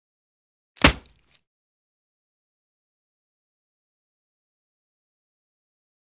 Одиночный удар Томагавк